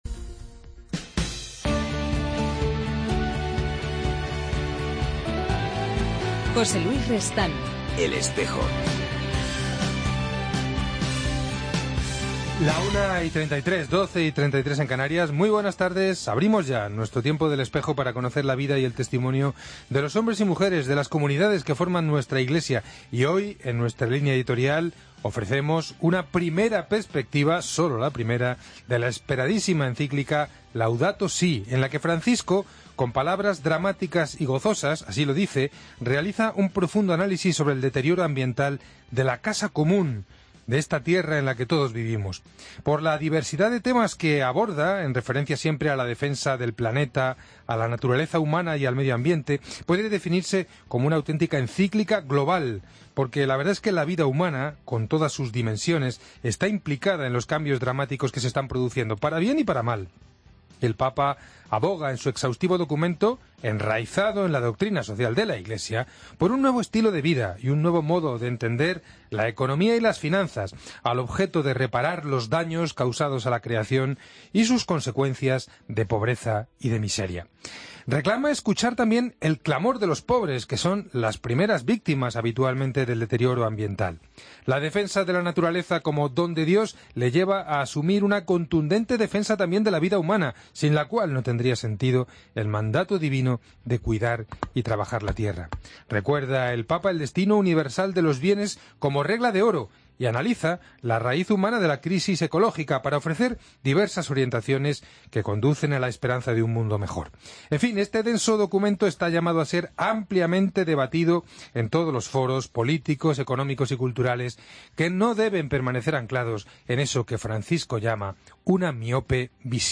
AUDIO: Os ofrecemos la primera entrevista del nuevo Observador permanente de la Santa Sede ante la FAO, el sacerdote español Fernando Chica Arellano.